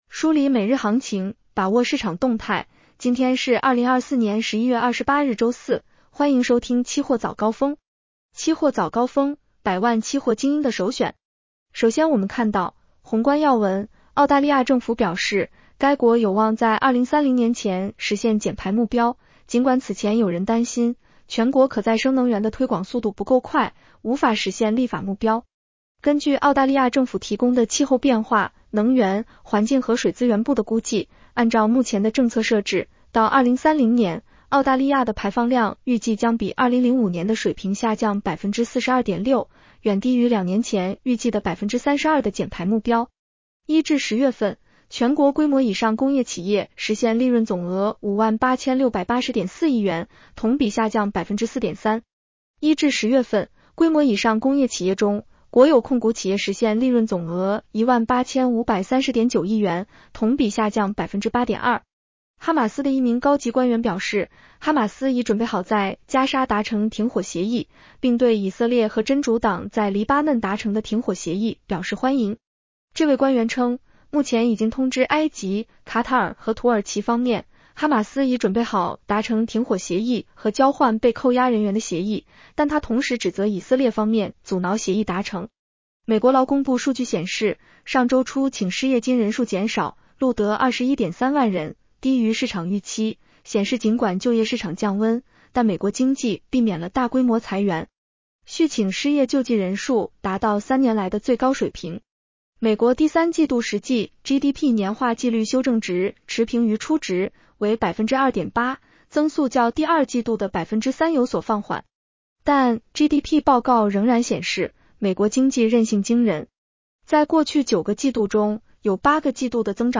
期货早高峰-音频版 女声普通话版 下载mp3 宏观要闻 1.澳大利亚政府表示，该国有望在2030年前实现减排目标，尽管此前有人担心，全国可再生能源的推广速度不够快，无法实现立法目标。